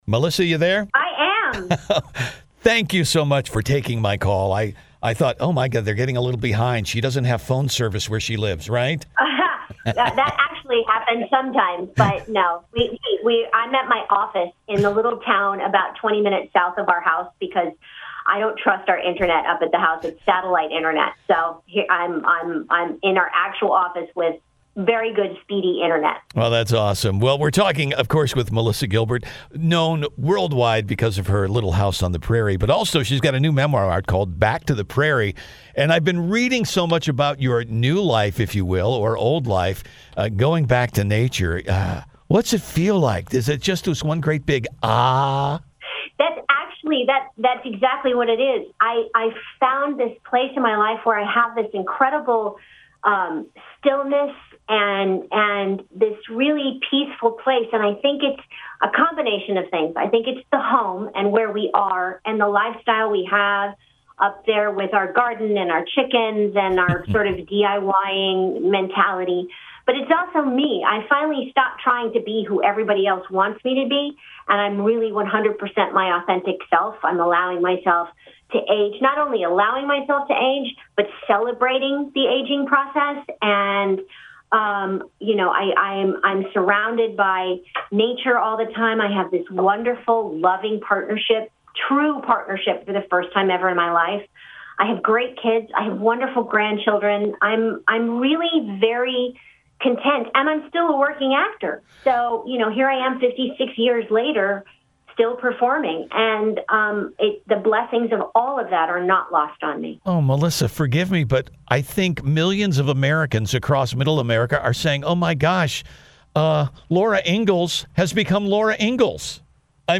Listen to this extraordinary interview with Melissa Gilbert as she talks about simplifying her life and the happiness she’s experienced. https